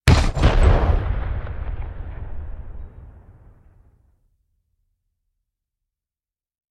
Звуки замедленного времени
Звук выстрела в замедленном воспроизведении